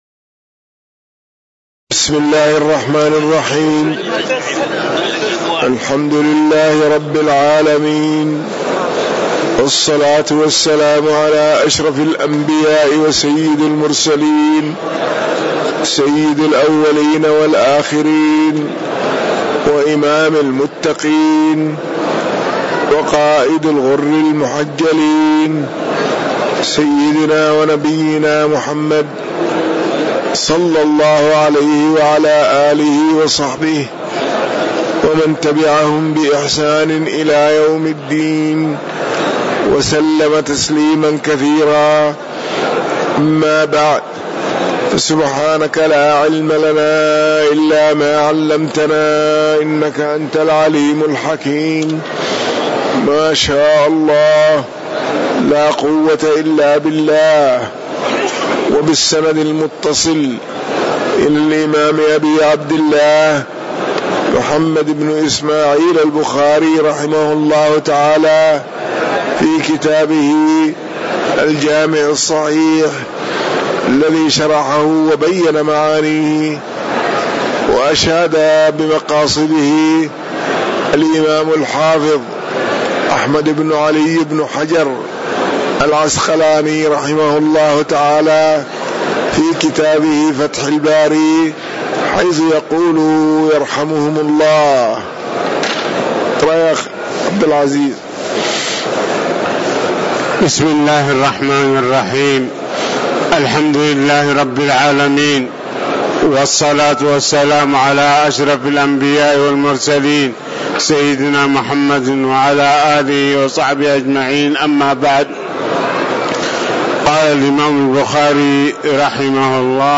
تاريخ النشر ١٤ جمادى الآخرة ١٤٤٠ هـ المكان: المسجد النبوي الشيخ